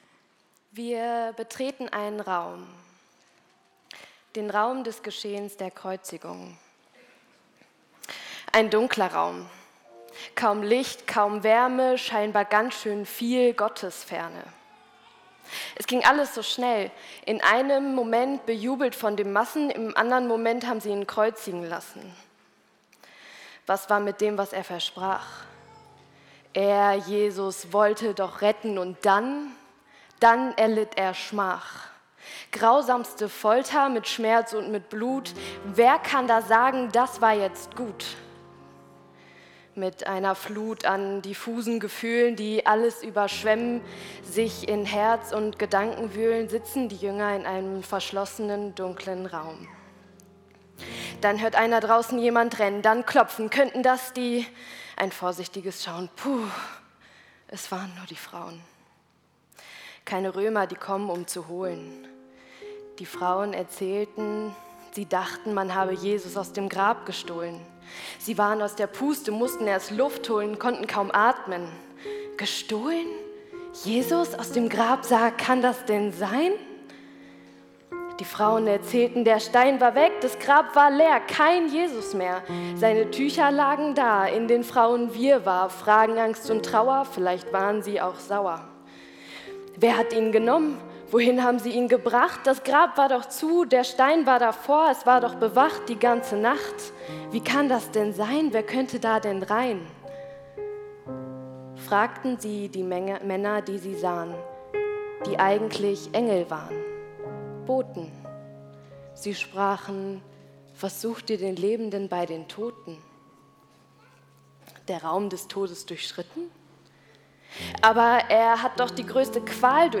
08_Poetry_Slam.mp3